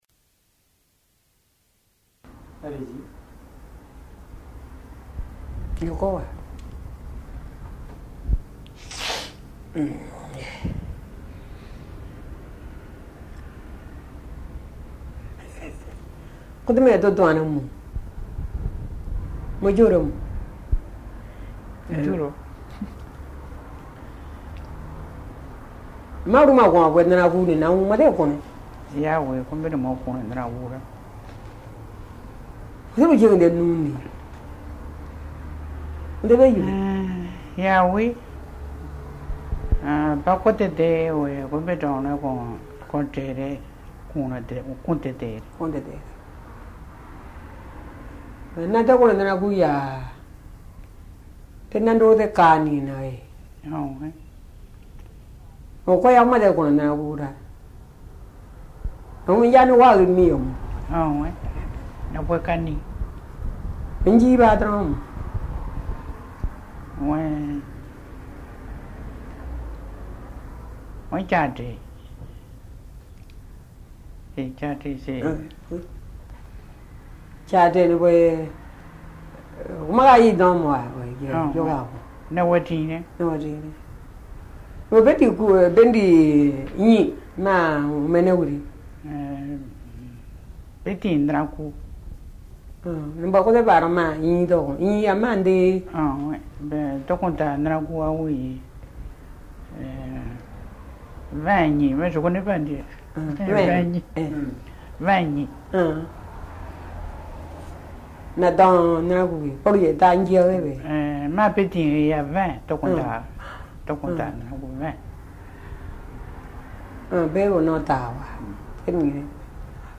Documents joints Dialogue leçon 05 ( MP3 - 3 Mio ) Un message, un commentaire ?